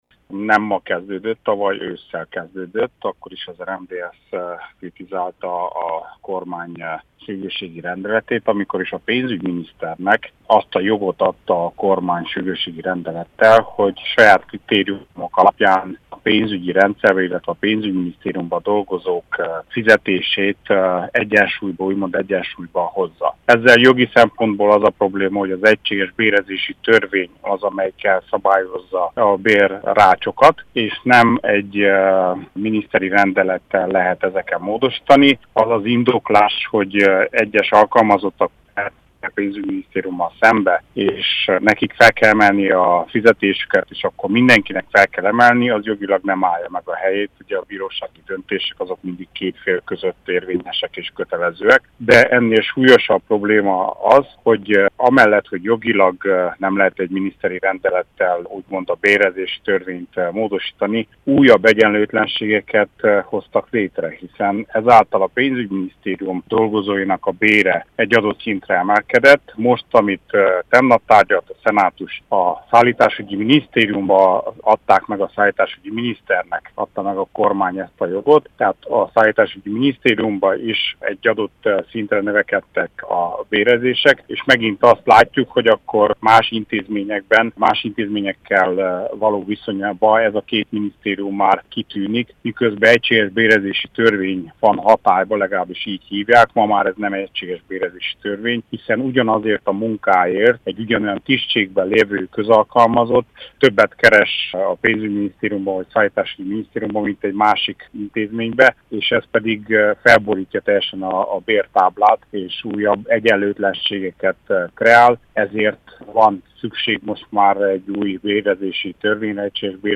interjúja.